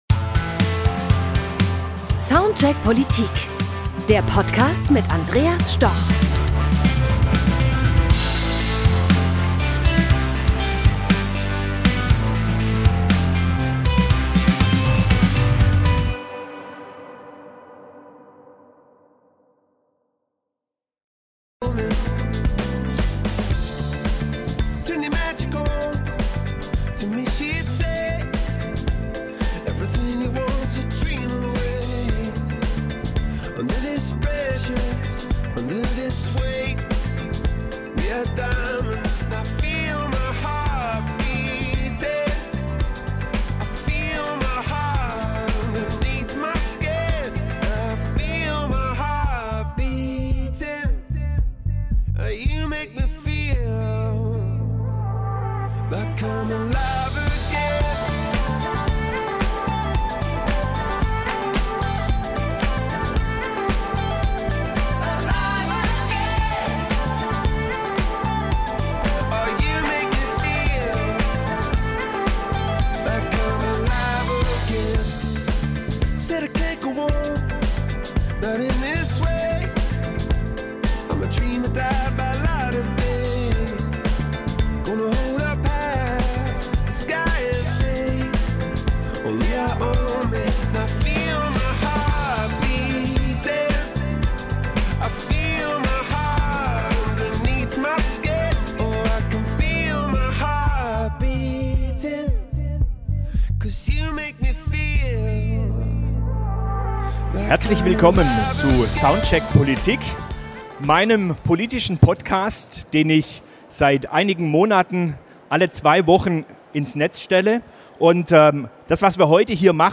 Gespräch mit CIO Bund, Dr. Markus Richter Verwaltungsdigitalisierung – ein langfristiges, komplexes Thema, das häufig kritisch betrachtet wird.